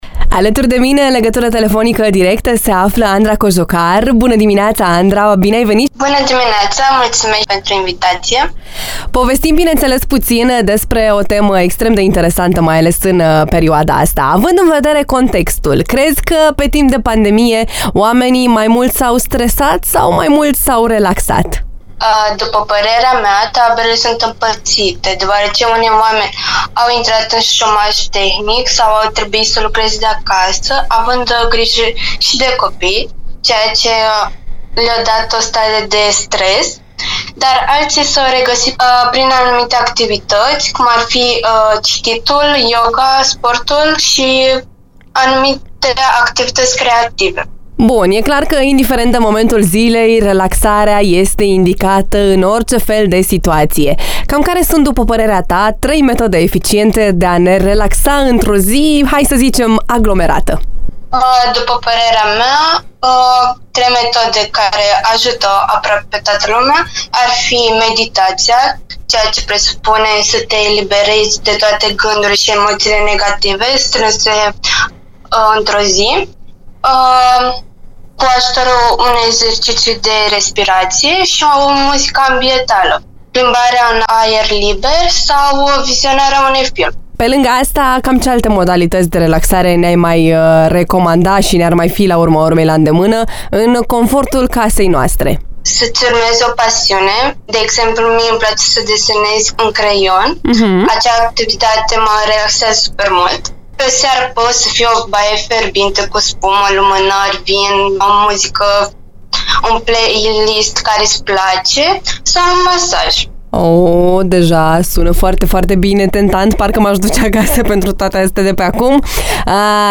Interviul integral, mai jos: